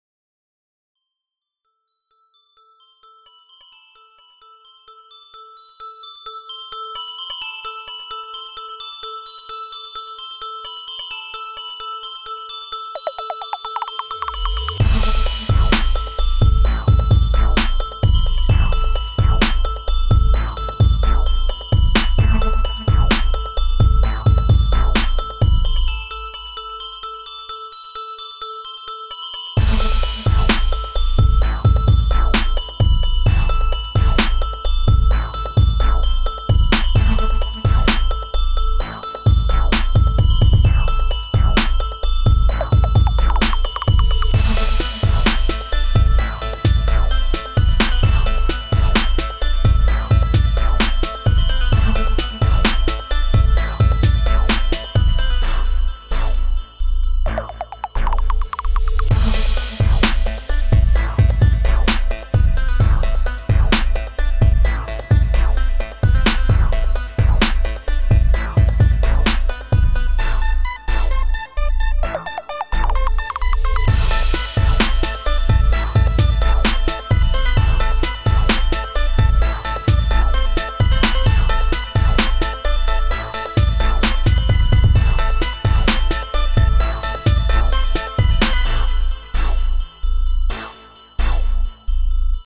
Скачать Минус
Стиль: Hip-Hop